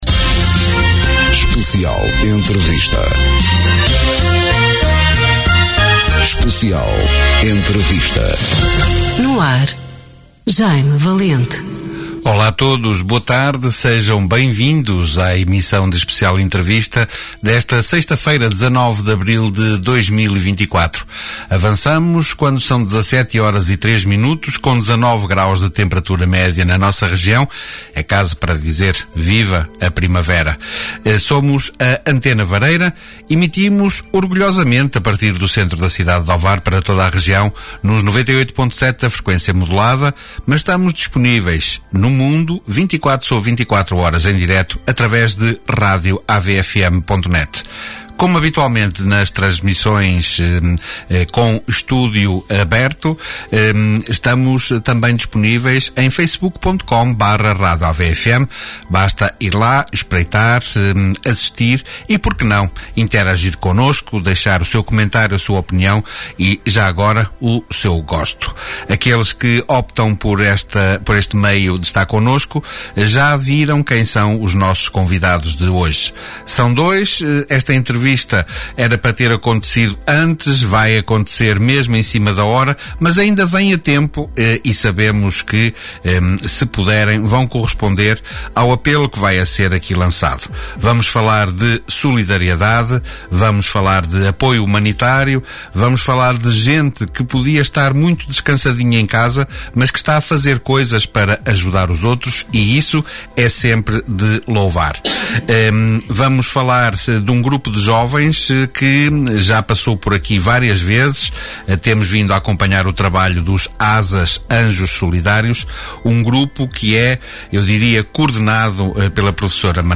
Especial Entrevista
Direitos reservados Especial Entrevista Conversas olhos nos olhos em direto Mais informações